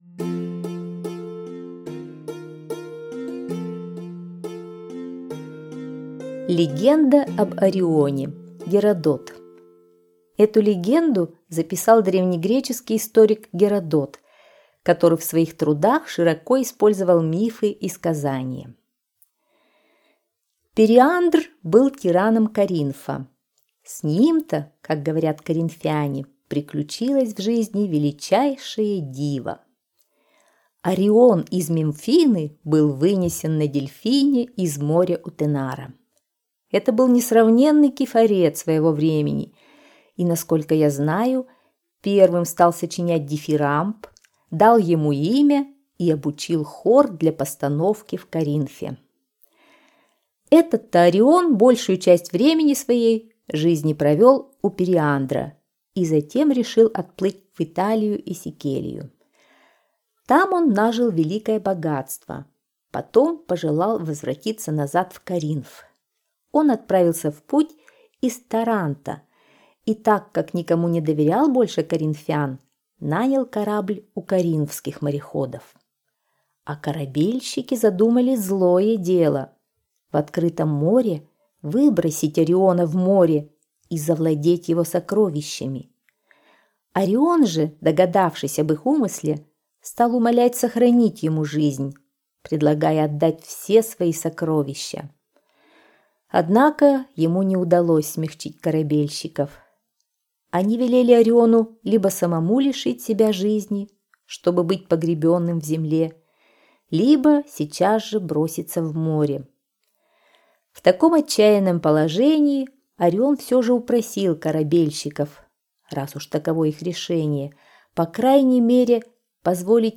Аудиосказка «Легенда об Арионе» – Геродот